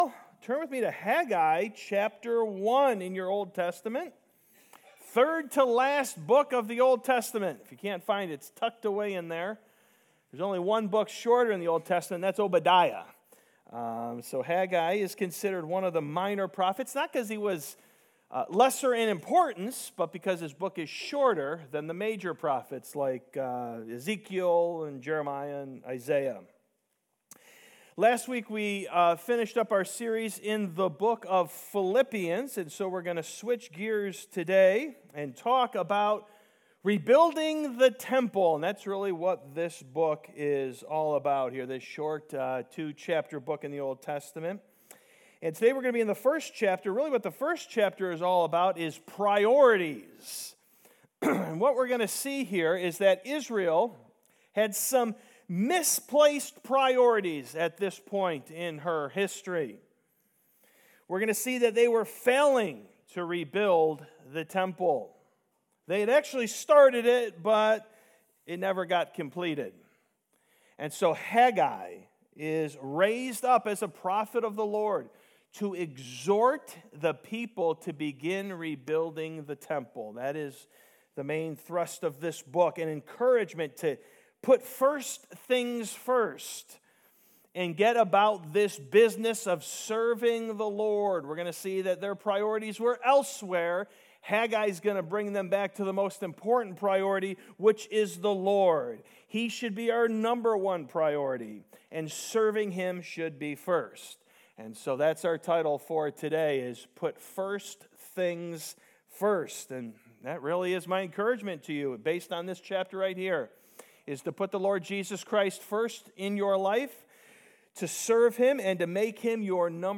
Preached Sunday Morning May 19, 2024